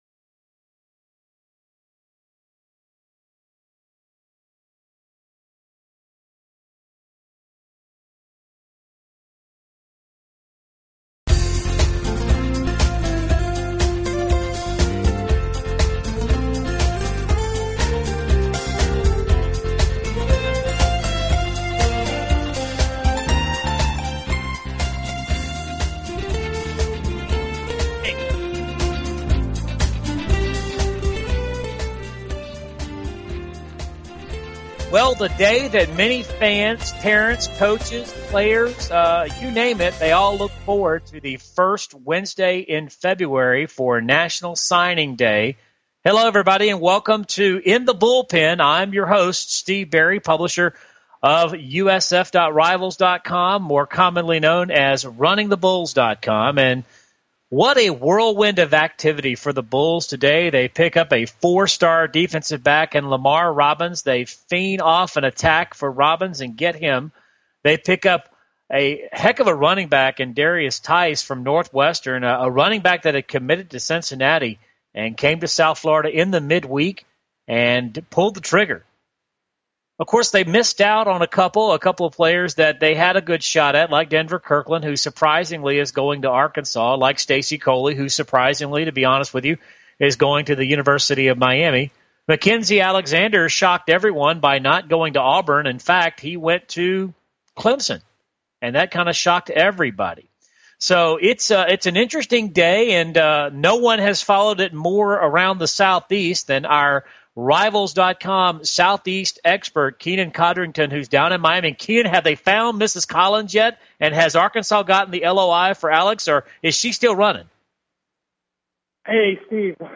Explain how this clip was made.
This week it's a special National Signing Day - and we'll be breaking down the 2013 signing class and taking your calls.